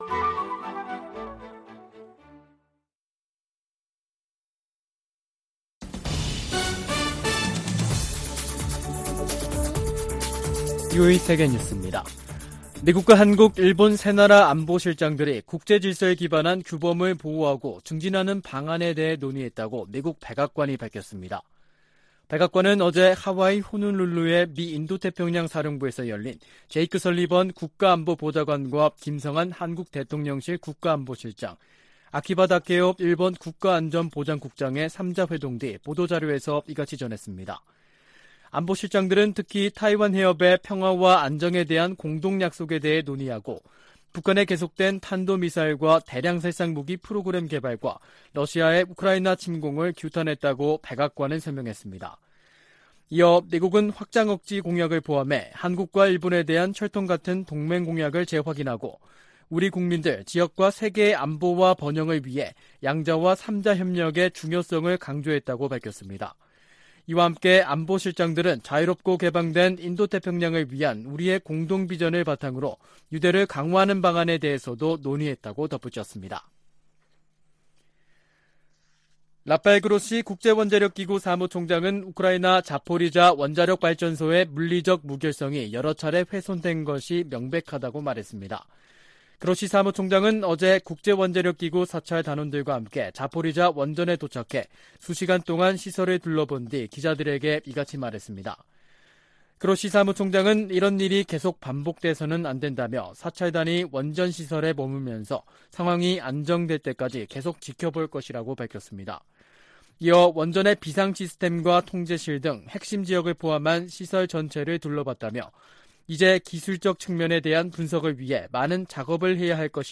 VOA 한국어 간판 뉴스 프로그램 '뉴스 투데이', 2022년 9월 2일 3부 방송입니다. 미국과 한국, 일본 안보실장들이 하와이에서 만나 북한 미사일 프로그램을 규탄하고 타이완해협 문제 등을 논의했습니다. 미국과 한국 간 경제 협력을 강화하기 위한 미 의원들의 움직임이 활발해지고 있습니다. 미국 검찰이 북한의 사이버 범죄 자금에 대한 공식 몰수 판결을 요청하는 문건을 제출했습니다.